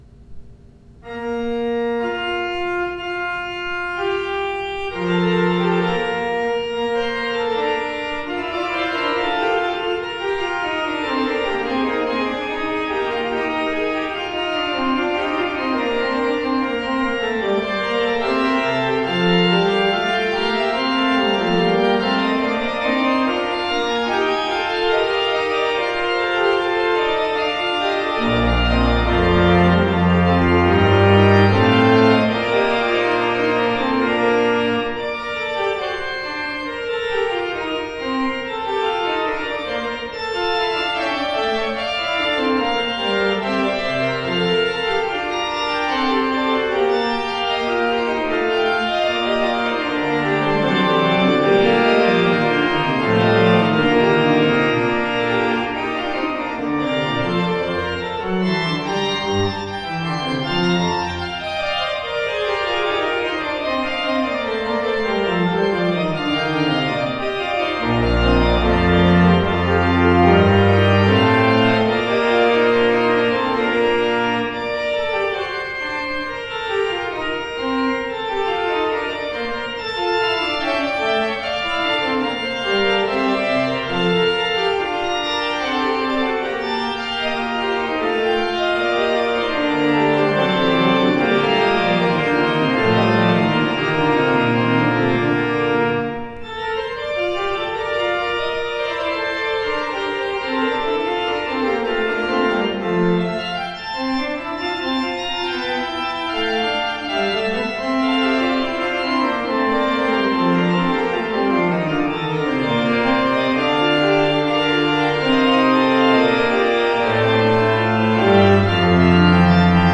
Postludes played at St George's East Ivanhoe 2016
The performances are as recorded on the Thursday evening prior the service in question and are made using a Zoom H4 digital recorder.